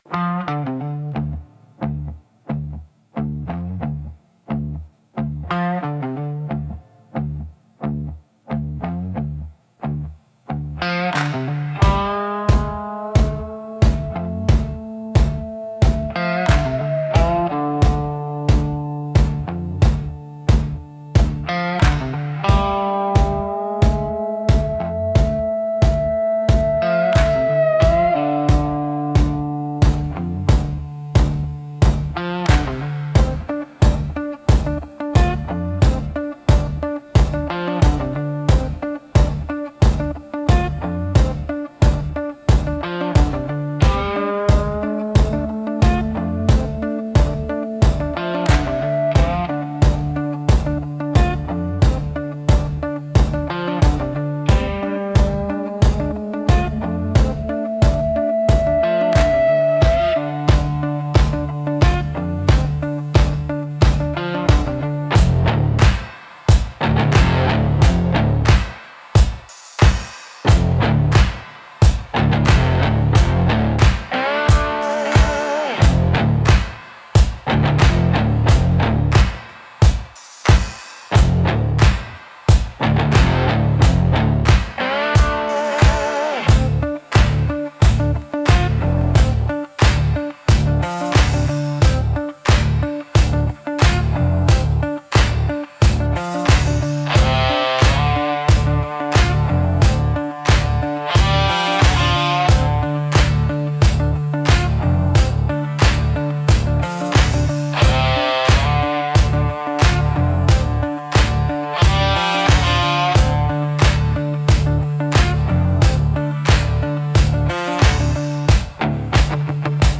Raw Country Blues